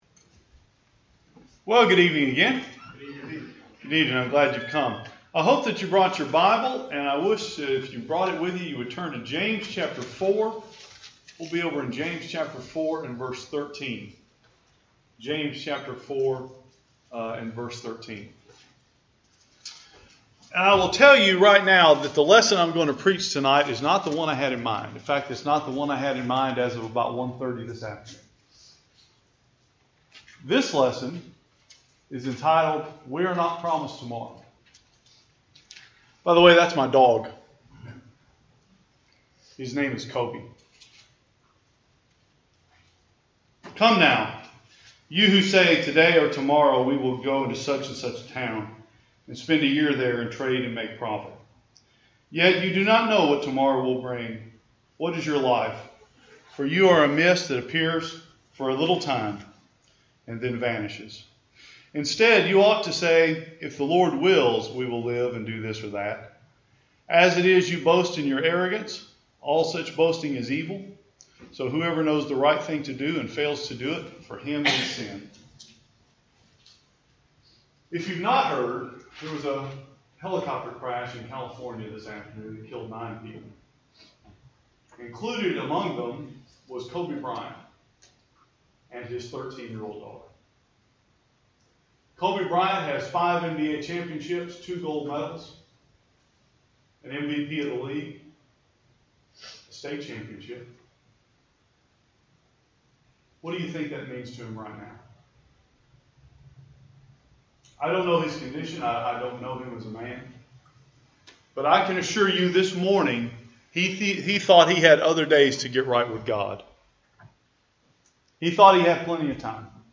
This sermon was preached on the day NBA star Kobe Bryant died along with his daughter and seven others in a helicopter crash.